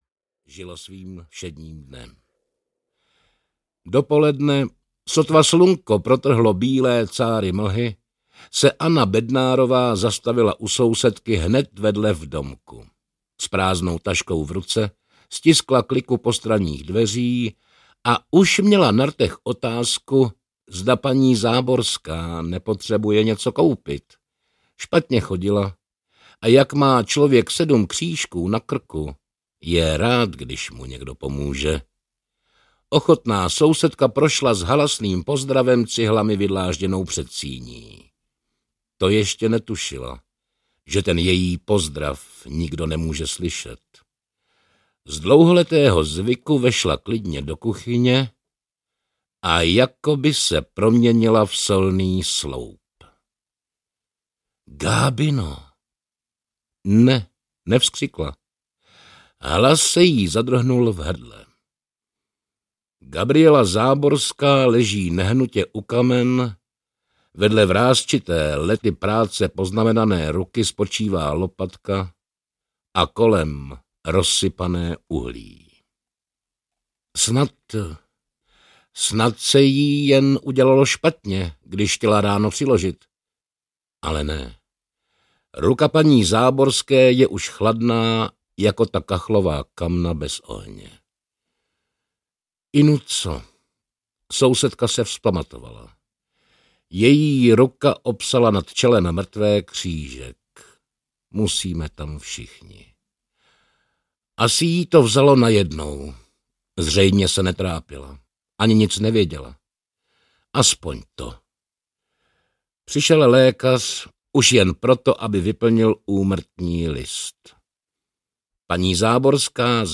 Přípitek s vrahem (slavné české kriminální příběhy) audiokniha
Ukázka z knihy
Napínavé povídky, jejichž interpretace se ujal herec Norbert Lichý. Deset detektivních příběhů spojuje zkušený vypravěčský styl a dokonalá znalost policejních postupů.
Četba Norberta Lichého, oceňovaného audioknižního interpreta, má spád a vtahuje posluchače do děje.